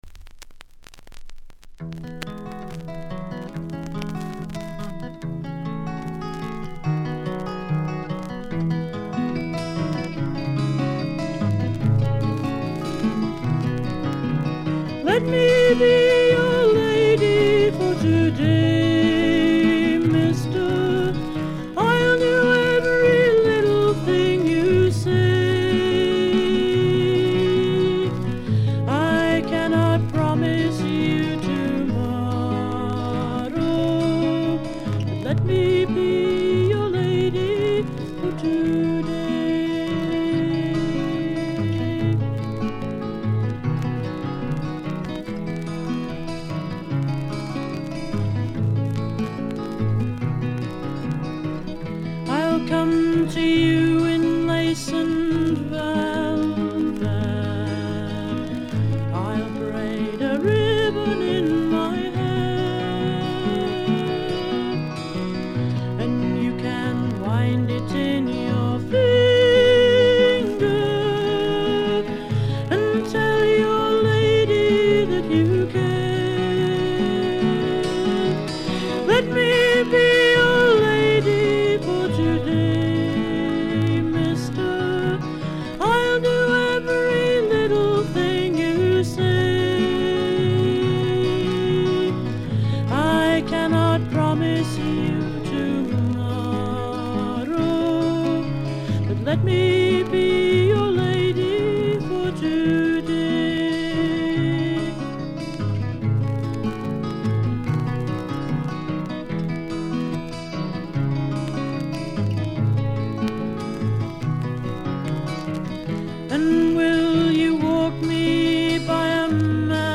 バックグラウンドノイズやや多め大きめですが、鑑賞を妨げるほどのノイズはありません。
試聴曲は現品からの取り込み音源です。